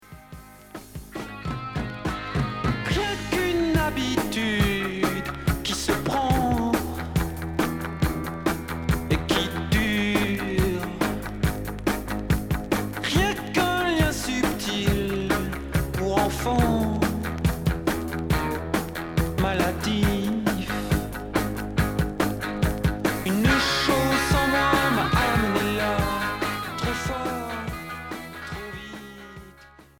Punk rock Quatrième 45t retour à l'accueil